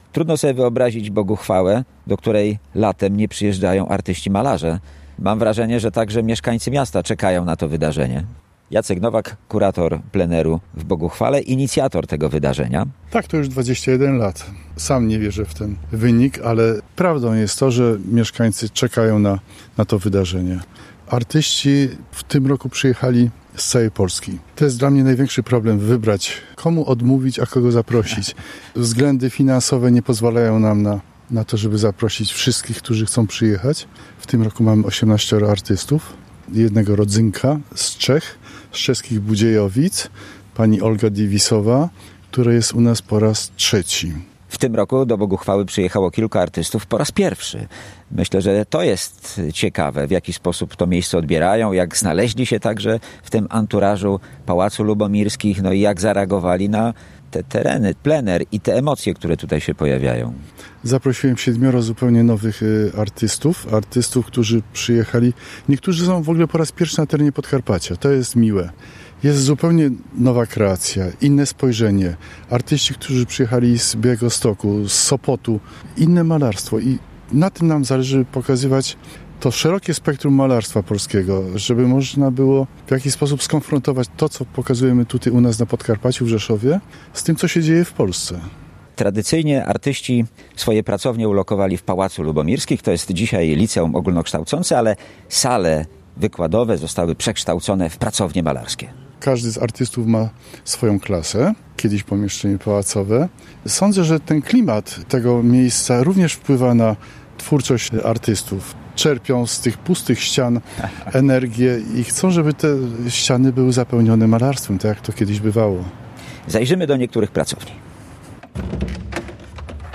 Z uczestnikami Pleneru Boguchwała 2025 rozmawia